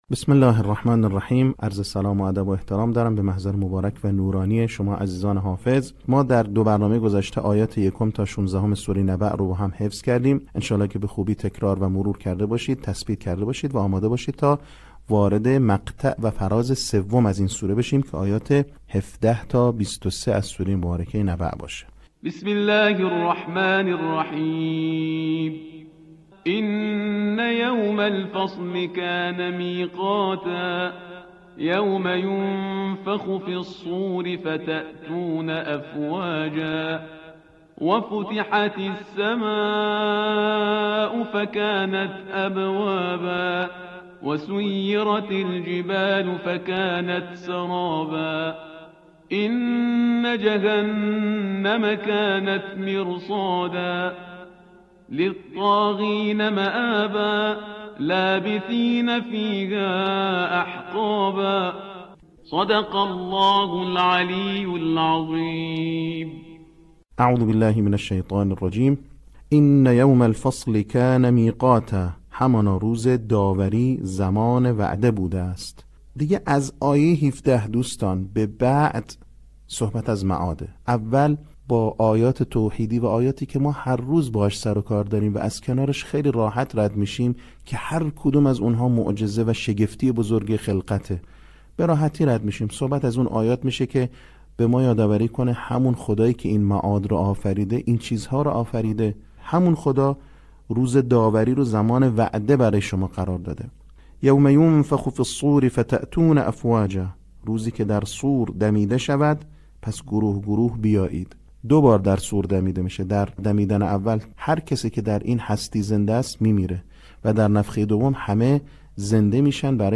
صوت | بخش سوم آموزش حفظ سوره نبأ